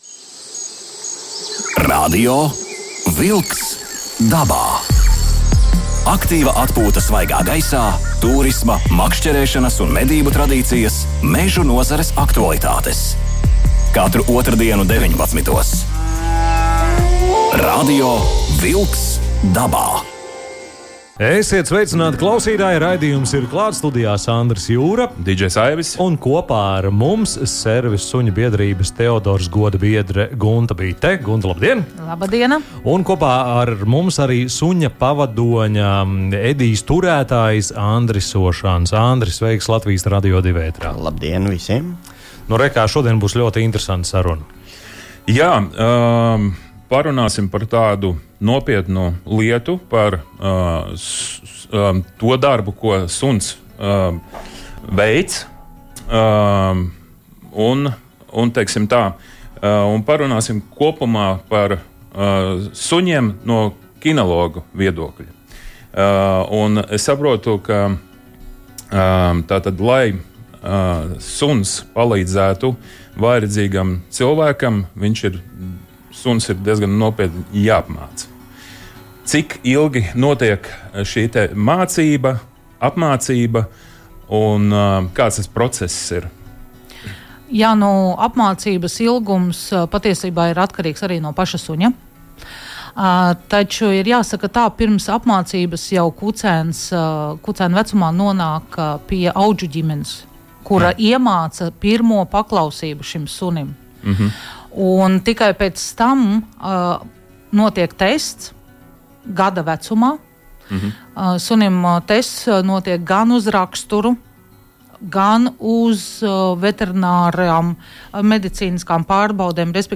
Studijā viesis
telefoniski stāsta